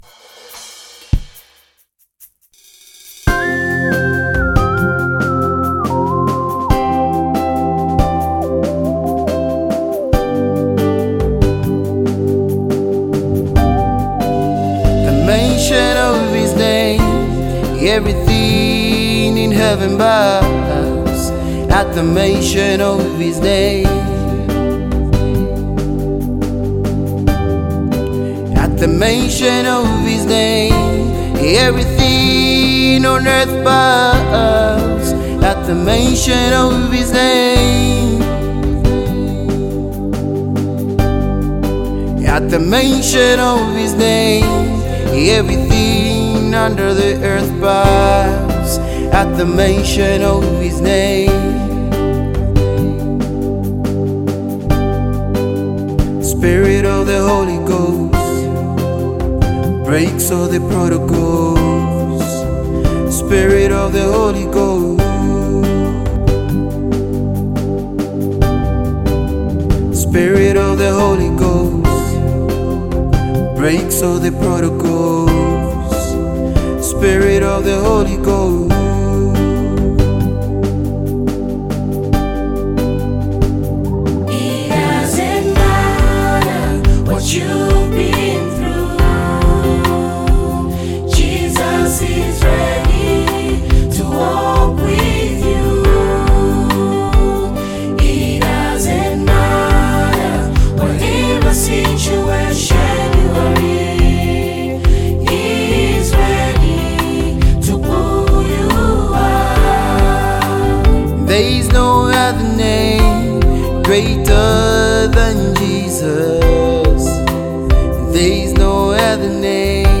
LatestZambian Worship Songs